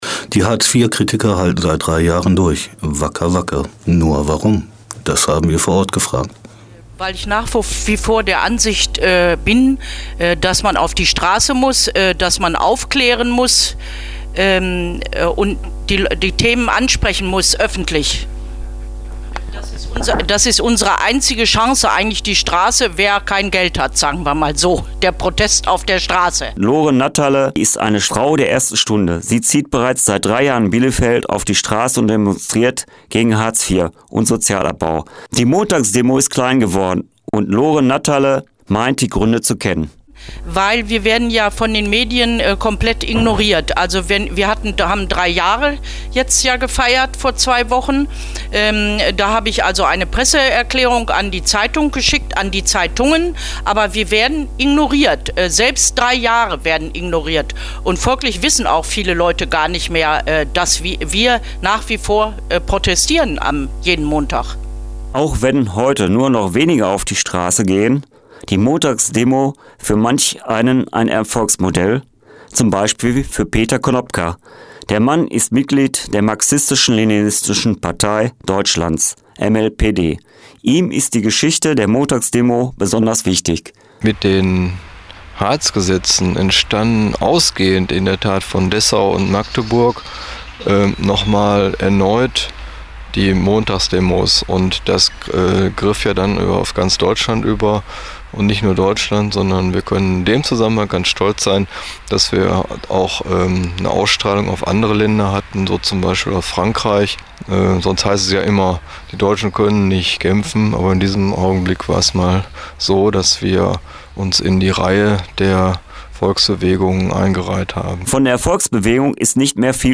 montagsdemo.mp3